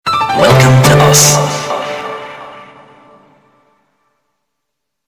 "welcome.wav" But I bass boosted it.
welcome-wav-but-i-bass-boosted-it.mp3